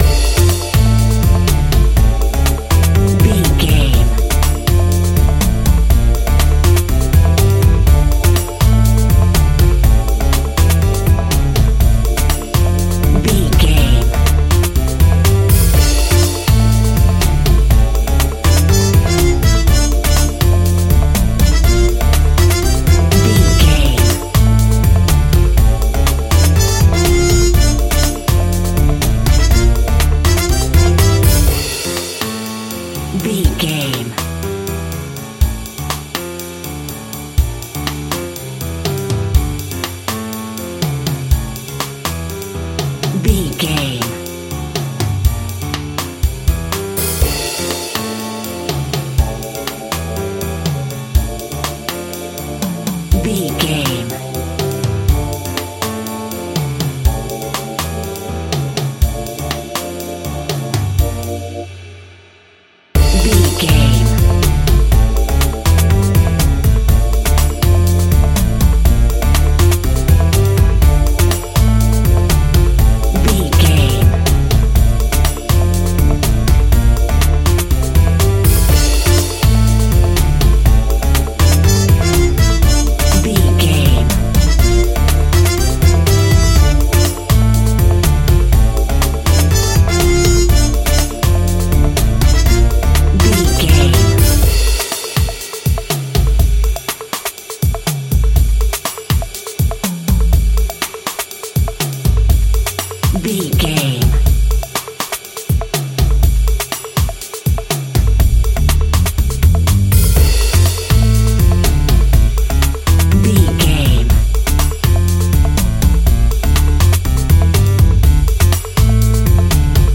Aeolian/Minor
world beat
tropical
strings
brass
percussion